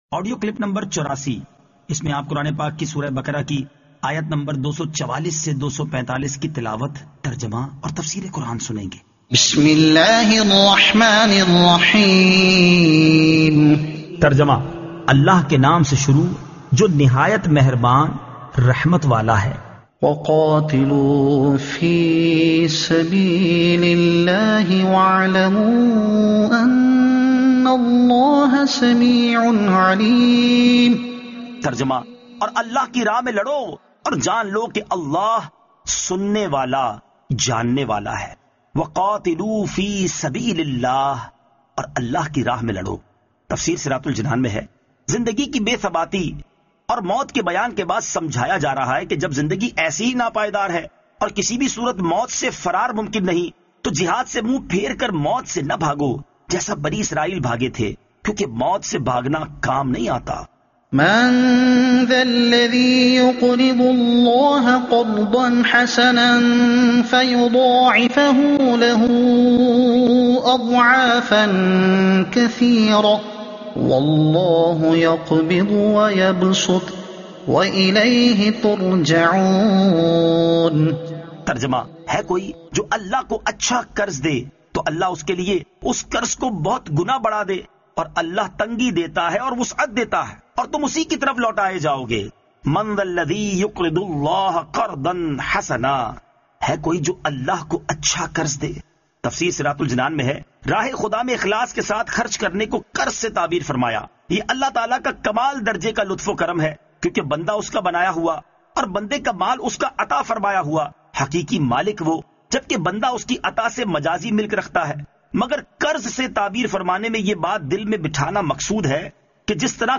Surah Al-Baqara Ayat 244 To 245 Tilawat , Tarjuma , Tafseer